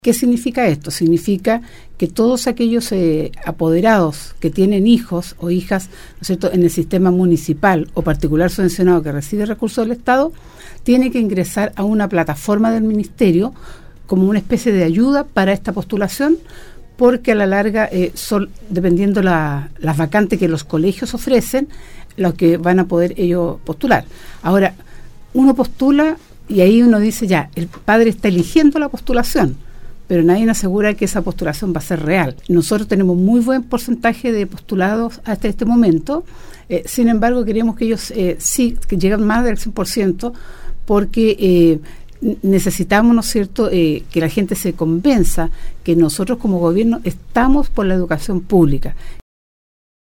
La seremi de educación Atacama, Silvia Álvarez, estuvo de visita en Nostálgica donde se refirió a las postulaciones para el Sistema Escolar Admisión (SAE) 2020 que iniciaron el pasado 13 de agosto y finaliza este 10 de septiembre en la Región de Atacama.